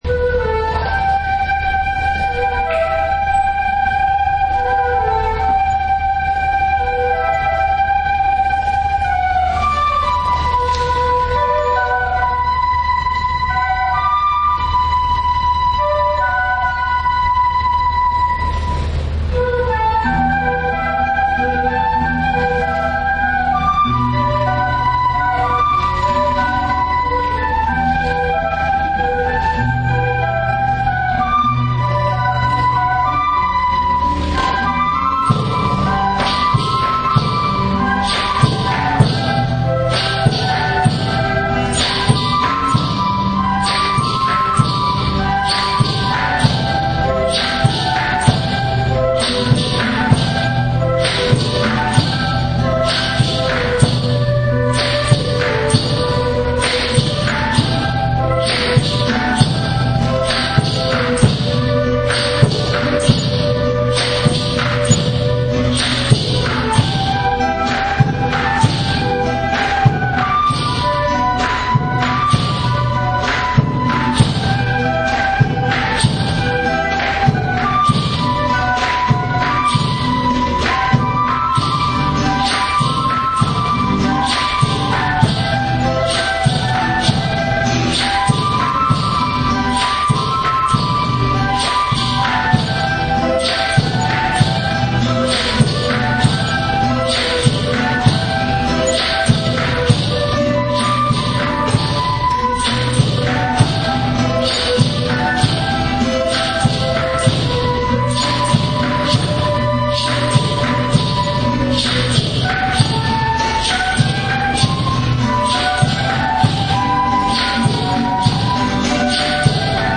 オカリナ曲名 ケーナ・サンポーニャ曲名